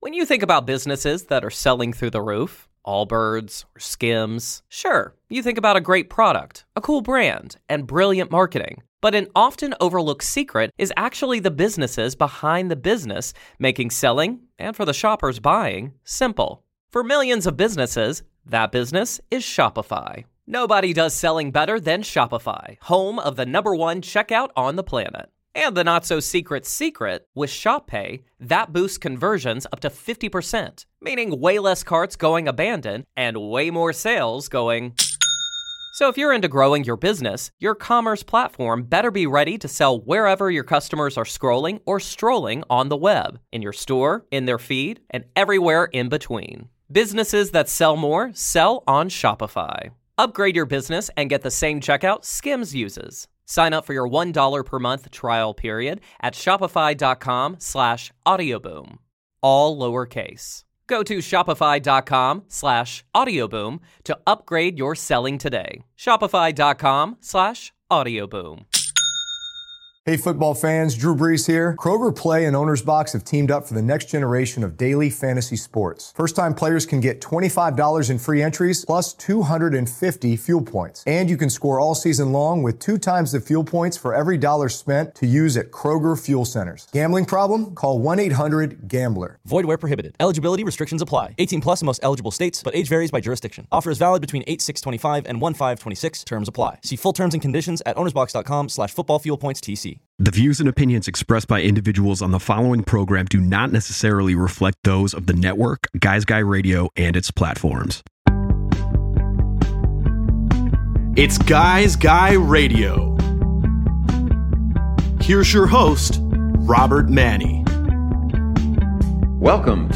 Guy’s Guy Radio features interviews and in-depth conversations with thought leaders across the worlds of relationships and modern masculinity, spirituality, health, wellness and diet, business, and much more.